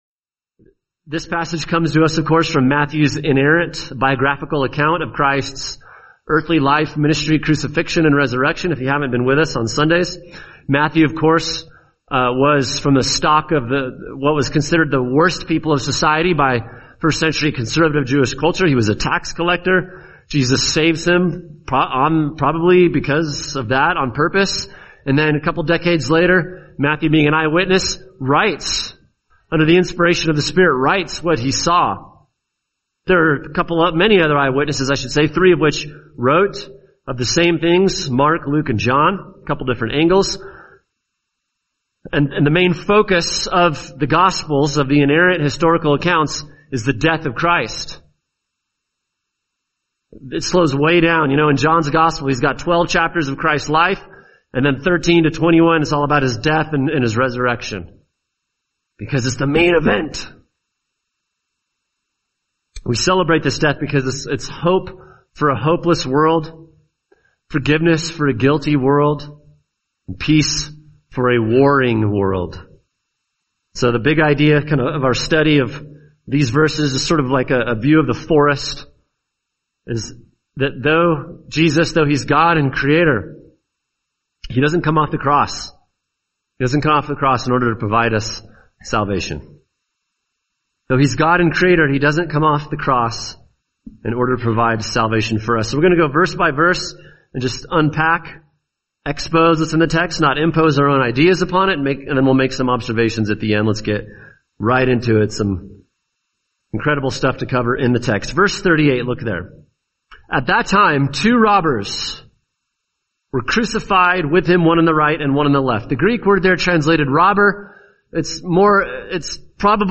[sermon] Matthew 27:38-44 Why Jesus Didn’t Come Off the Cross | Cornerstone Church - Jackson Hole